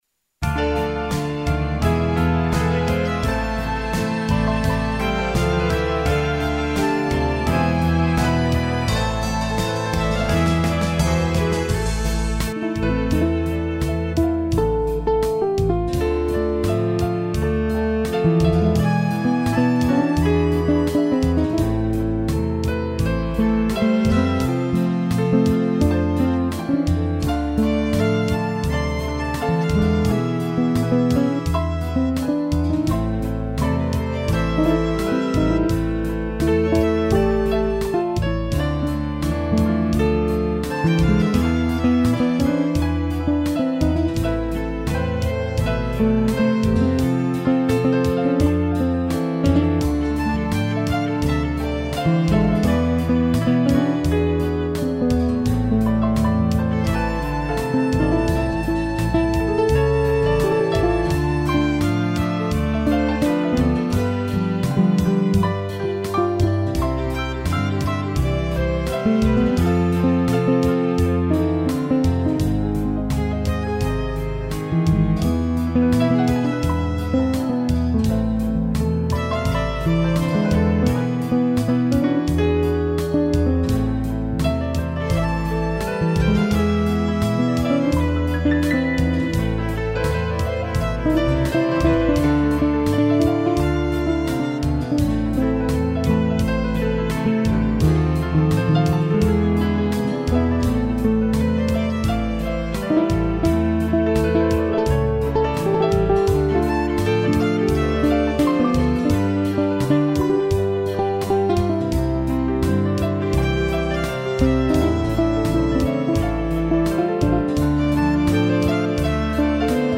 piano, cello e violino